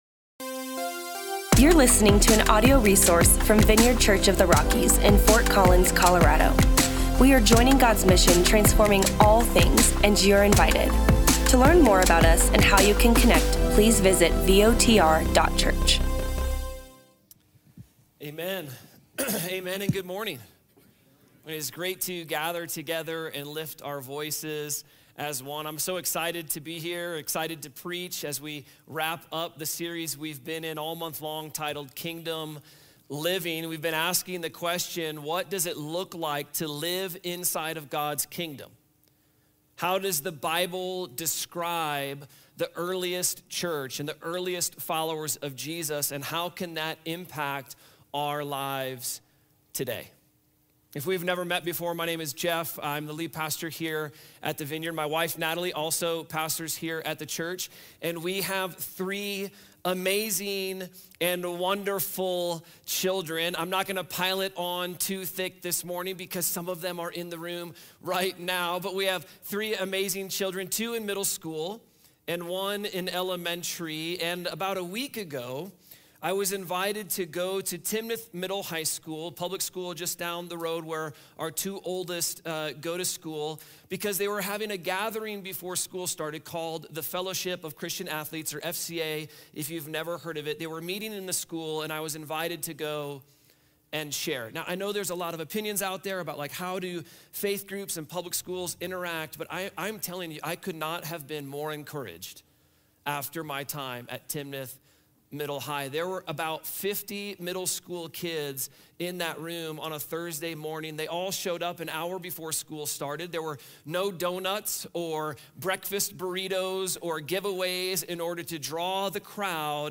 VINEYARD CHURCH OF THE ROCKIES Saved From (For) What?